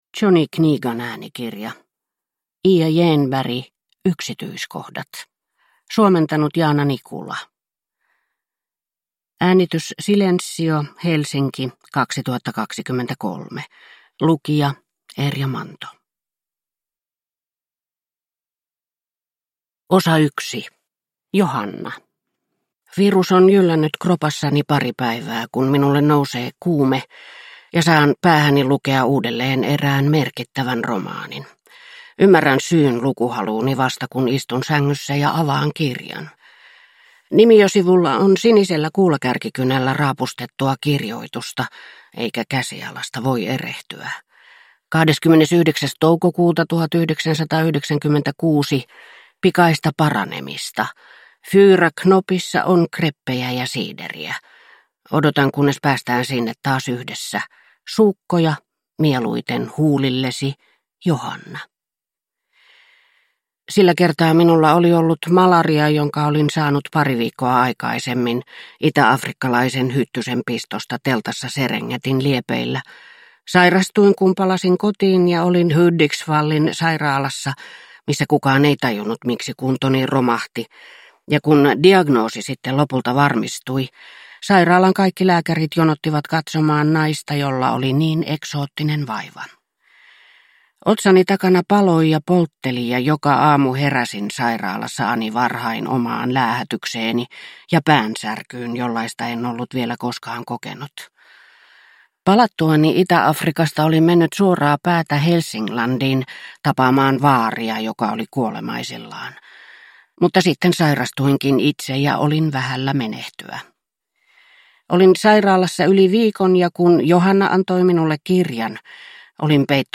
Yksityiskohdat – Ljudbok